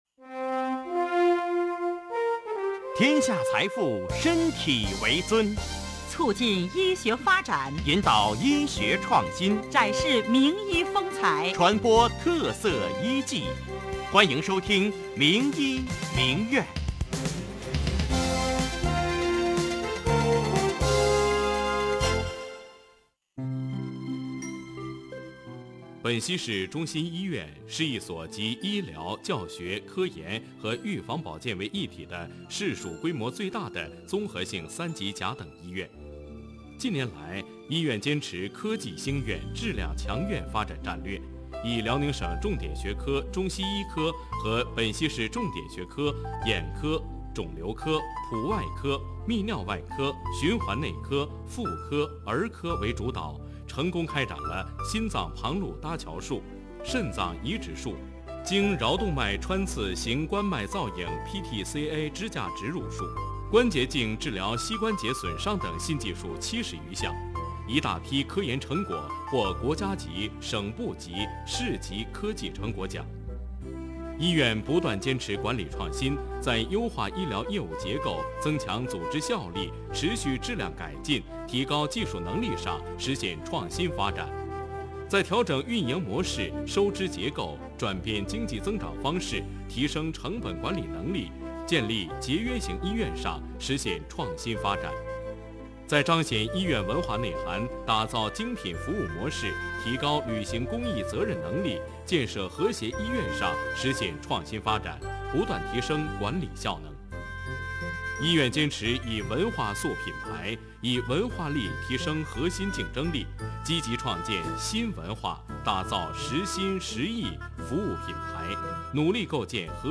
做客本溪市中心医院与本溪电台联办的《名医名院》栏目，从健康管理专家的角度，与山城的听众朋友们谈谈关于“减肥”的话题，阐述减肥的原则和误区，并介绍了如何利用控制体重来预防慢性疾病的发生等内容，积极促进健康科普知识及健康理念的普及。